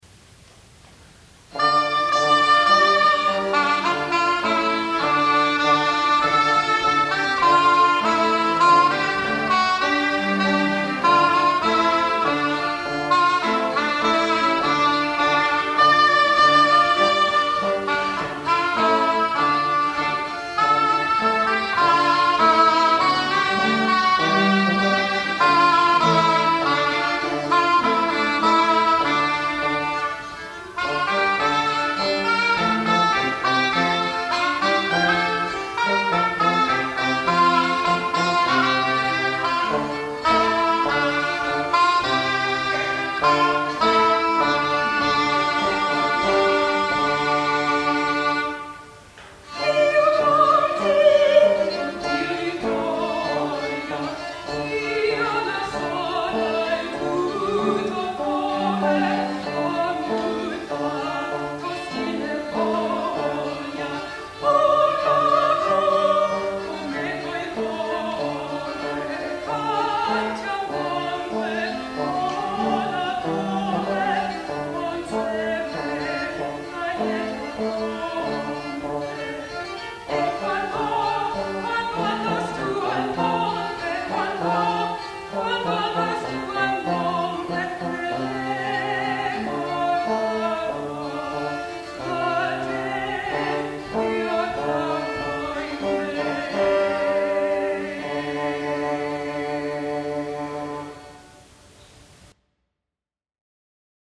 soprano
shawm